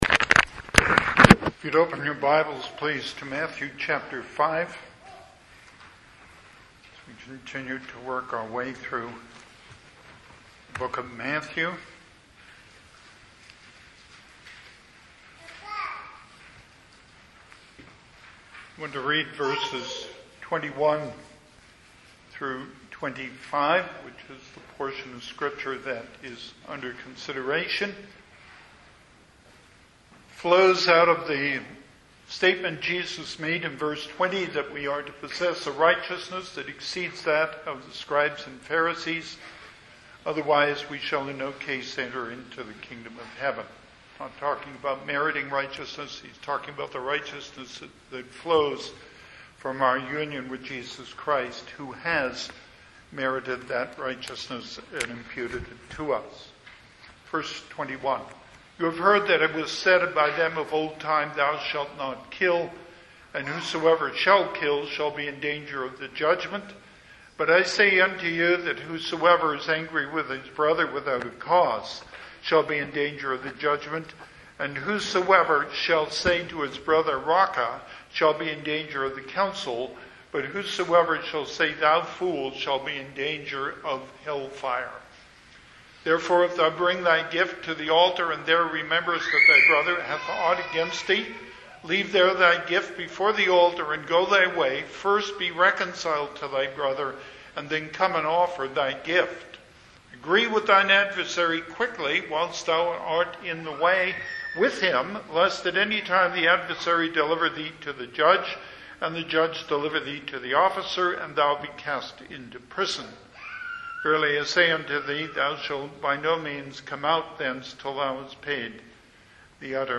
Passage: Matthew 5:23-24 Service Type: Sunday AM